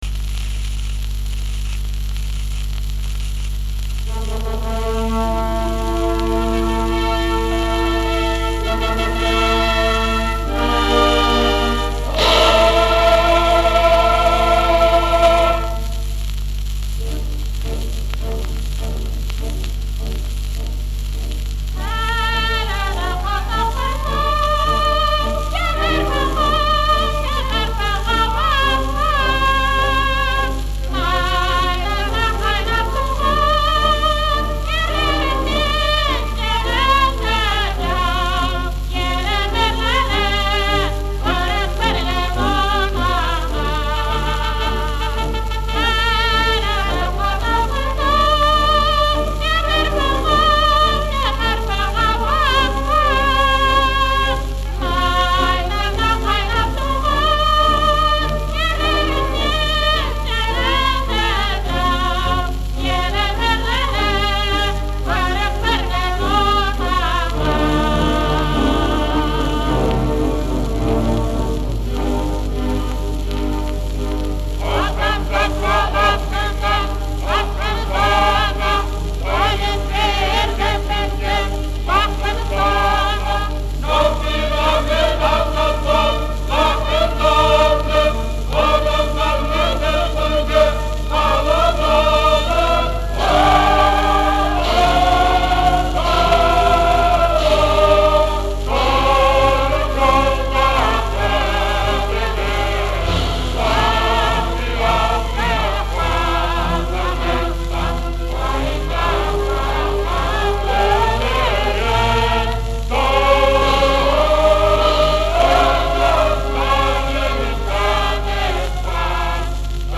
Описание: По-восточному вычурно